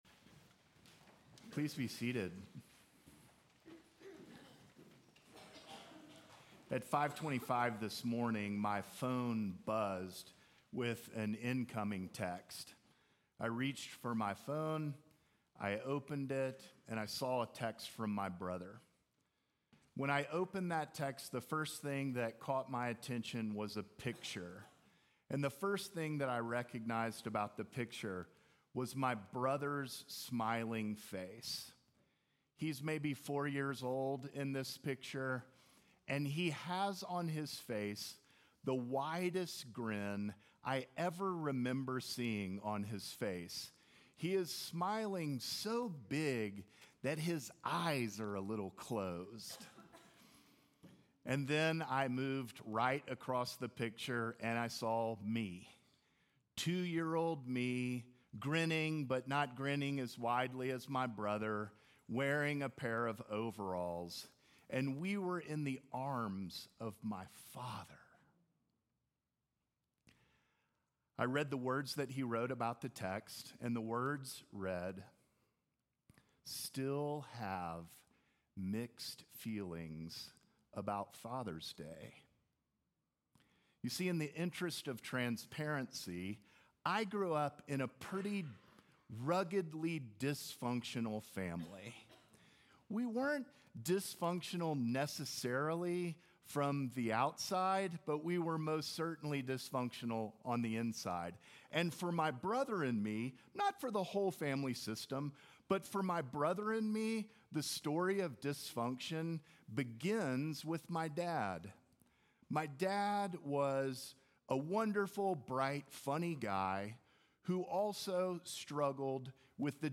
Sermons from St. John's Episcopal Church Trinity Sunday